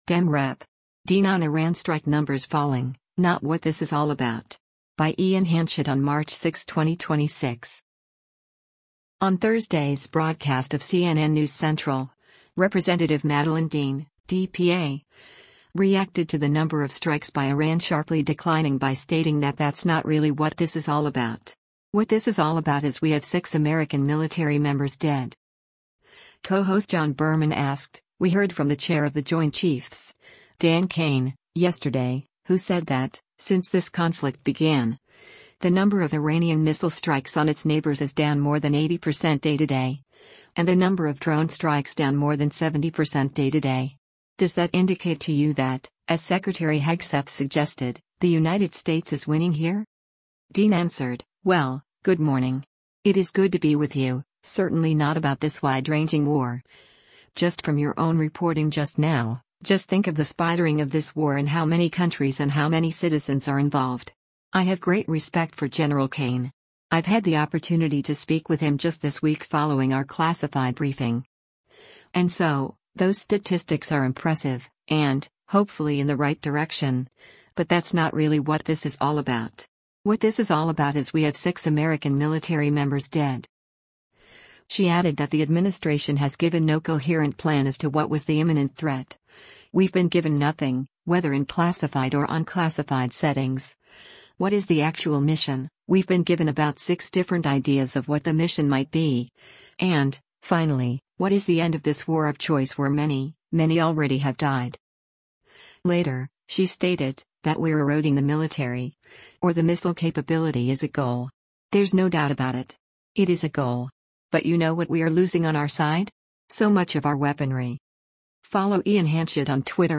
On Thursday’s broadcast of “CNN News Central,” Rep. Madeleine Dean (D-PA) reacted to the number of strikes by Iran sharply declining by stating that “that’s not really what this is all about. What this is all about is we have six American military members dead.”